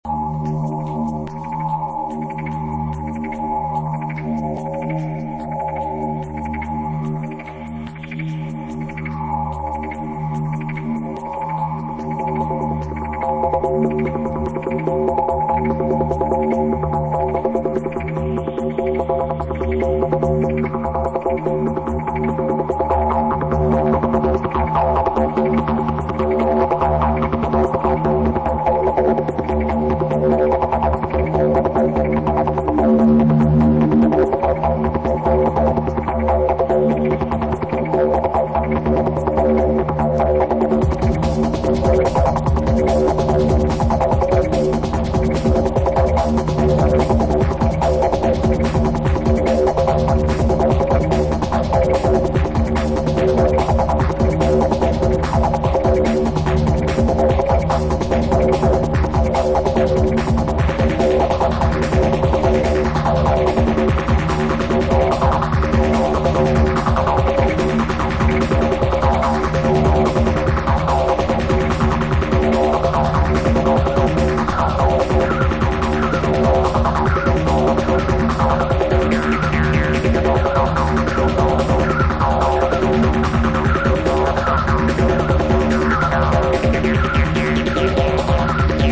IDM/Electronica, Electro, Techno